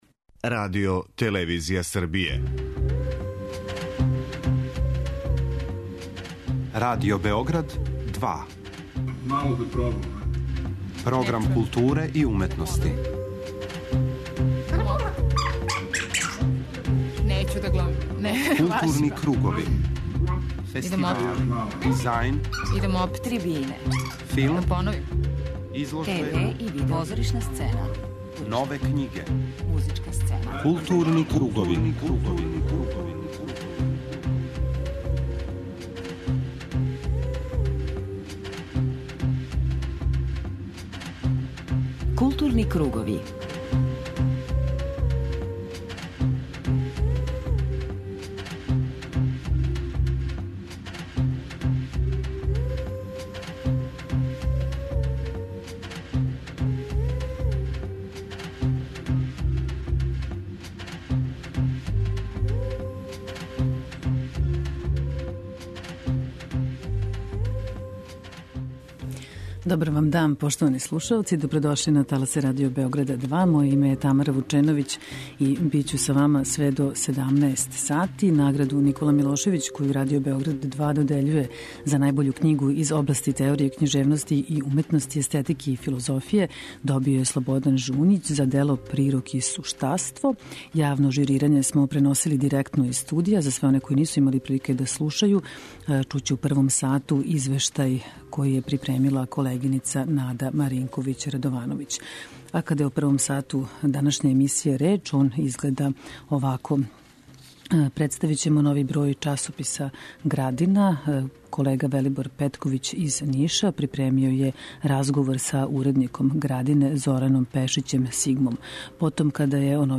преузми : 54.53 MB Културни кругови Autor: Група аутора Централна културно-уметничка емисија Радио Београда 2.